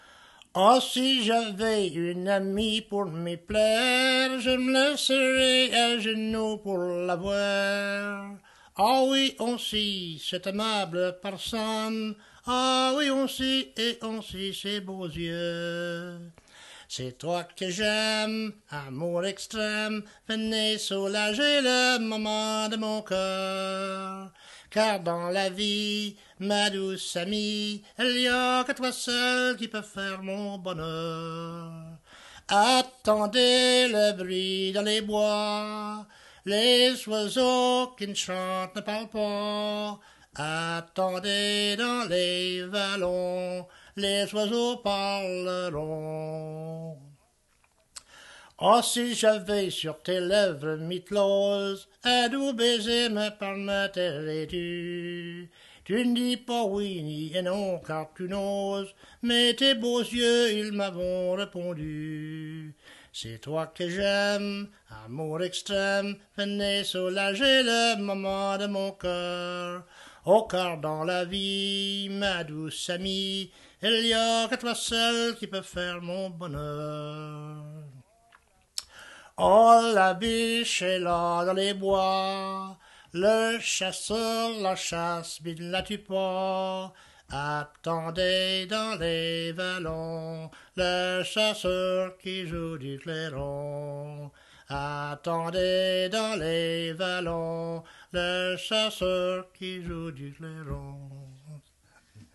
Chanson Item Type Metadata
Cap St-Georges